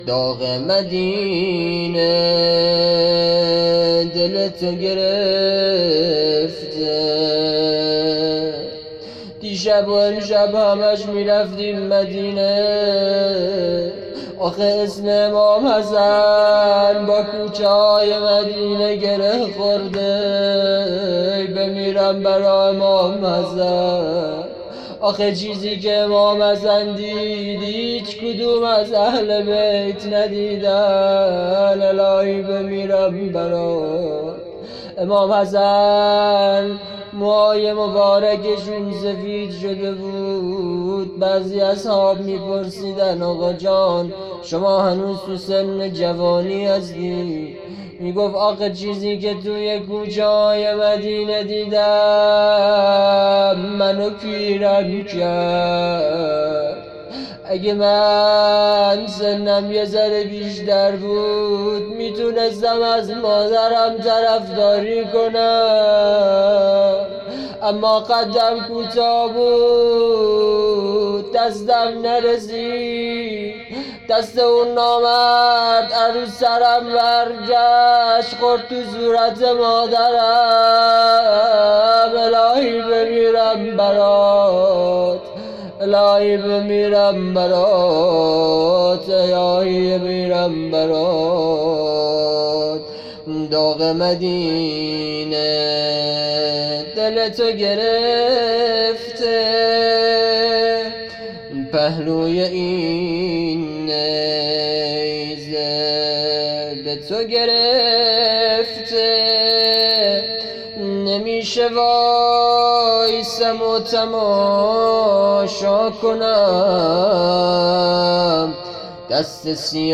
روضه
روضه قاسم بن الحسن (ع)
محرم 1400